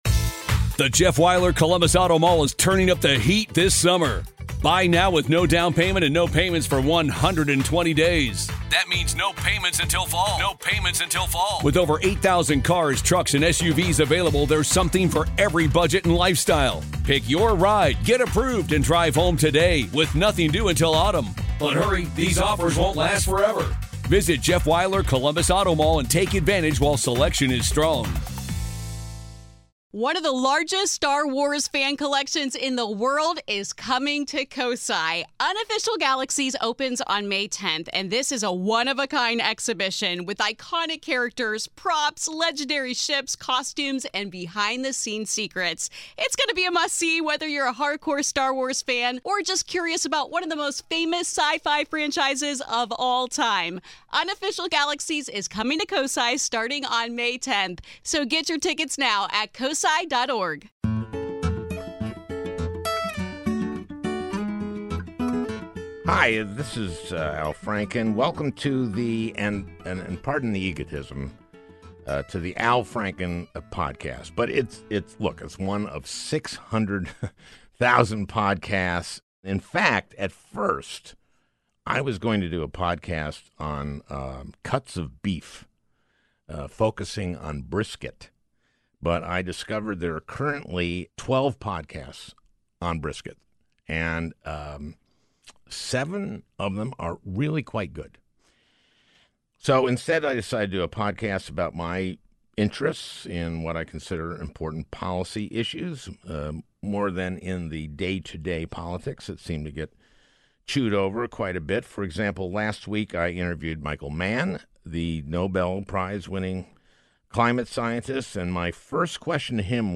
A Conversation with David Mandel